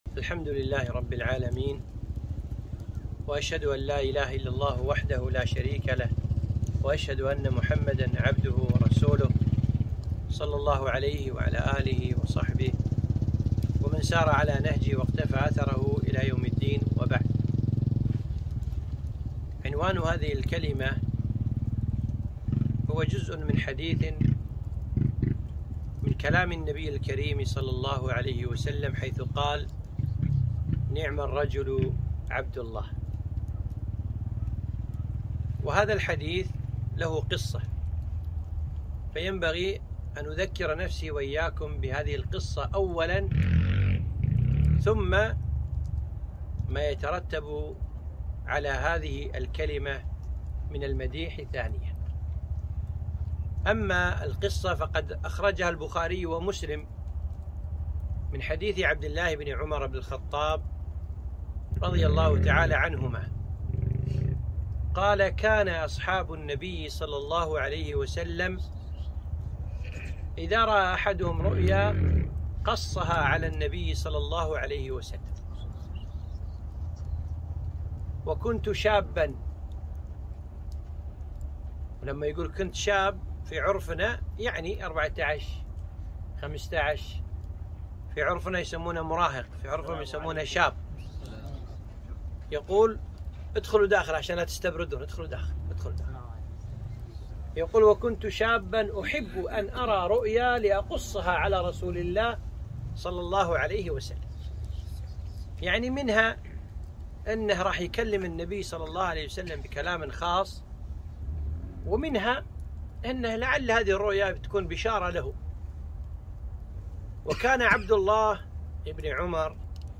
محاضرة - نِعْمَ الرجُل عبدالله